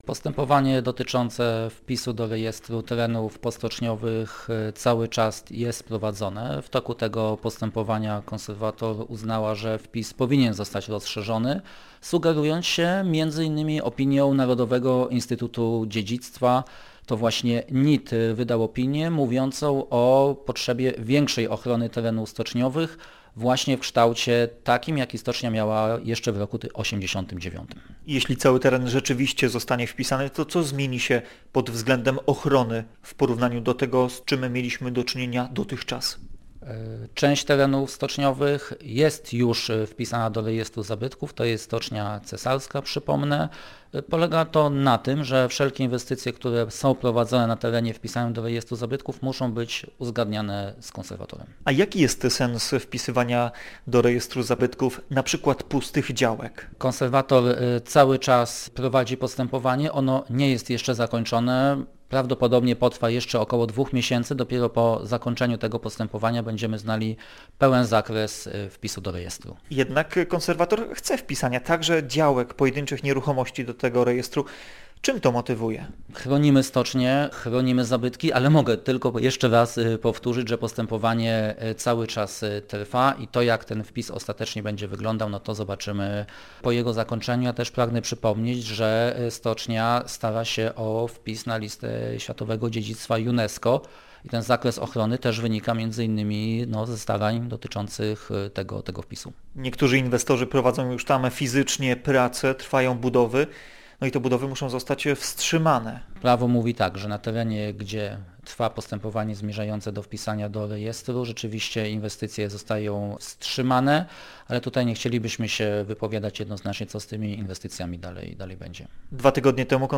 Więcej na temat tej procedury w rozmowie